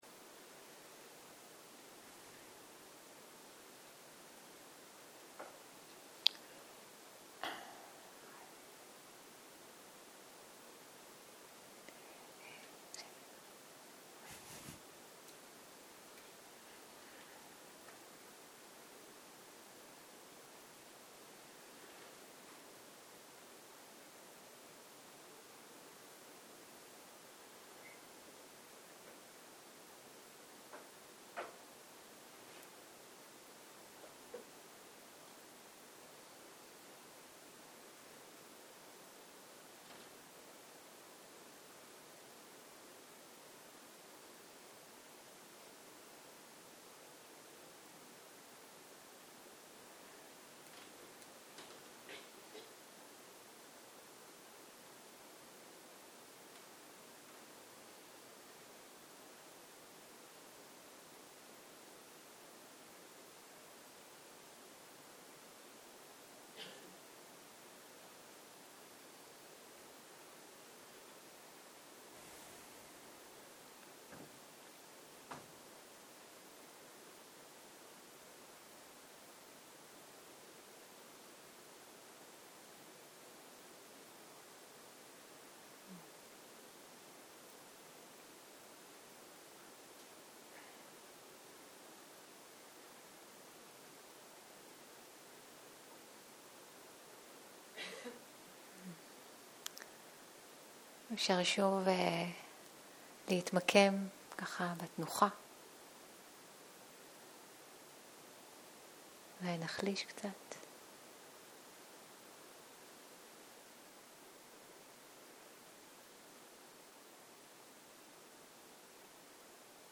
בוקר - הנחיות מדיטציה - תרגול מדיטציה עם היסודות ועם מחשבות לא מיטיבות 0:00 0:00 סוג ההקלטה: סוג ההקלטה: שיחת הנחיות למדיטציה שפת ההקלטה: שפת ההקלטה: עברית